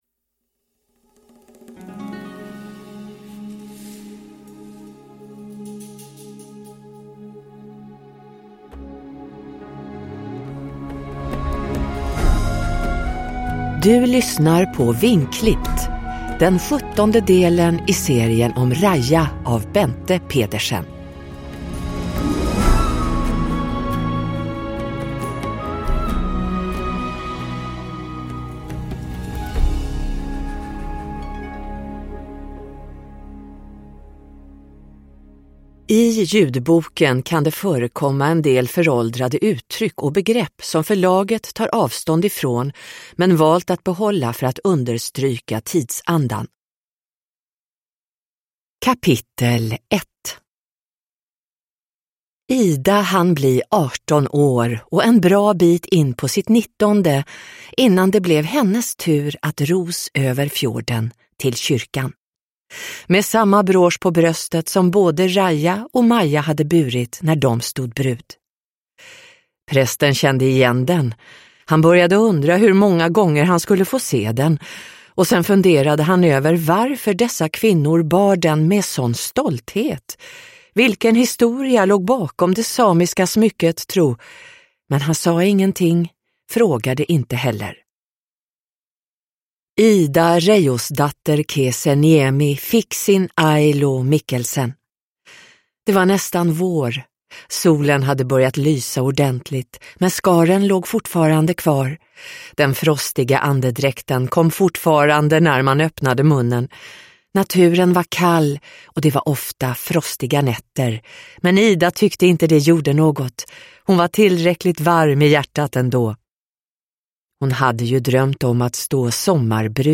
Vingklippt – Ljudbok